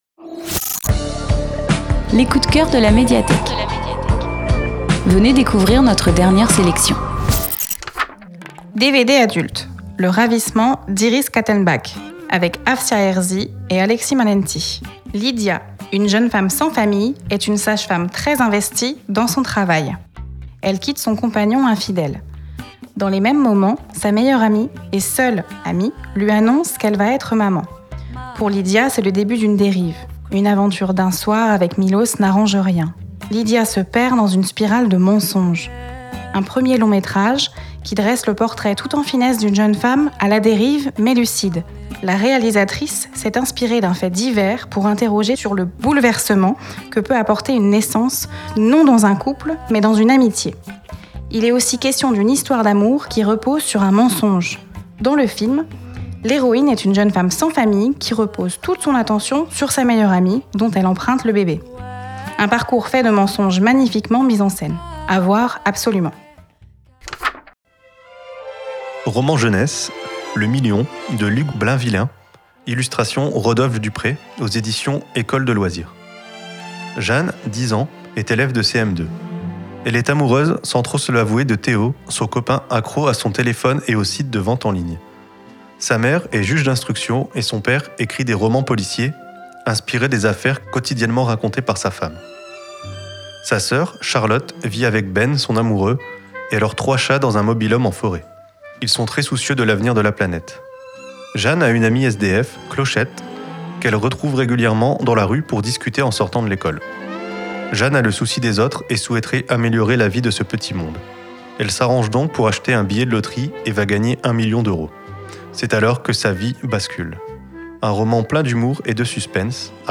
Tous les mardis à 15h00, l’équipe de la Médiathèque de Villebon vous présente ses quelques coups de cœurs : livres, DVD, événements…